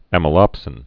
(ămə-lŏpsĭn)